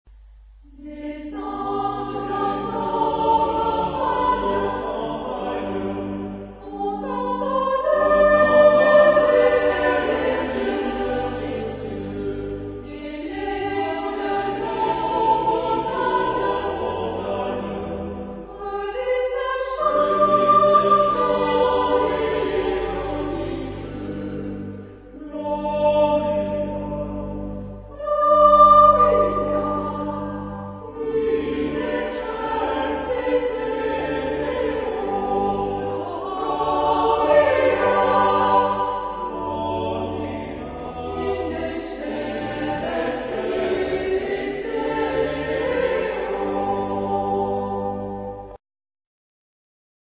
Noël ancien
Genre-Stil-Form: Kirchenlied
Charakter des Stückes: allegretto ; fröhlich
Chorgattung: SATB  (4 gemischter Chor Stimmen )
Tonart(en): G-Dur